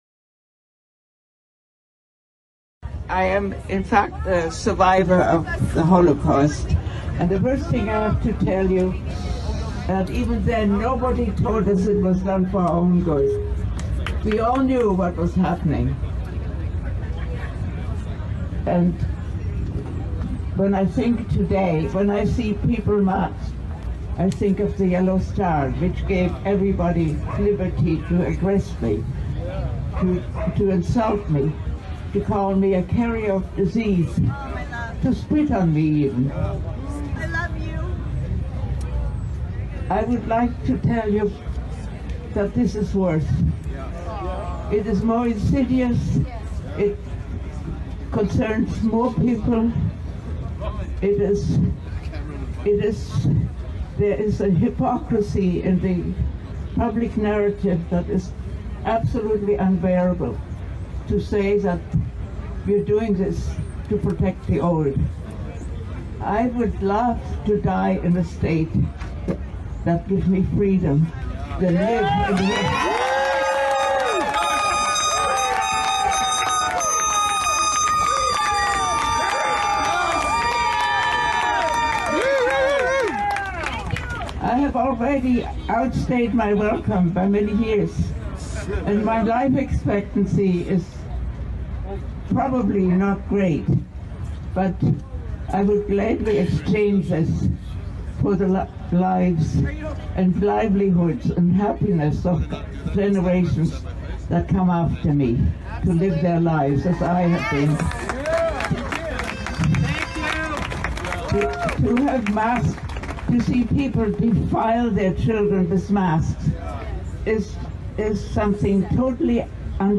ניצולת שואה מדברת על המצב